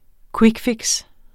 Udtale [ ˈkvigˌfegs ]